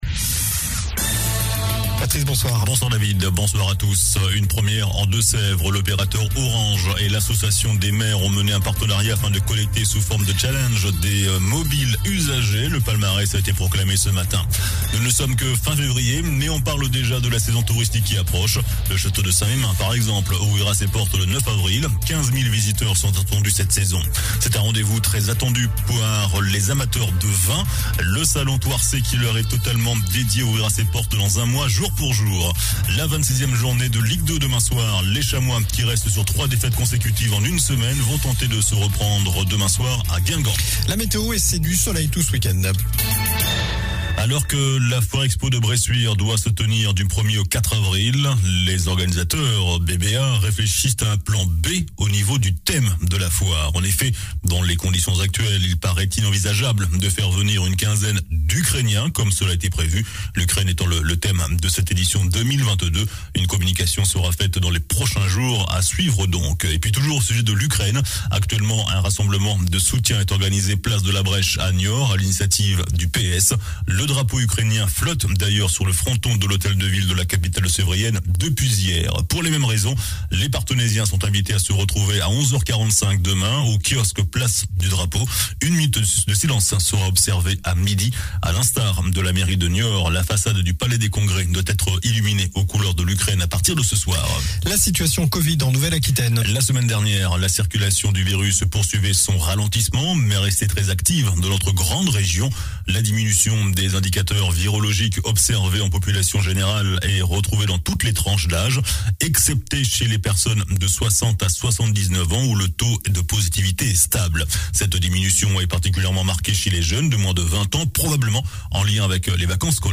JOURNAL DU VENDREDI 25 FEVRIER ( SOIR )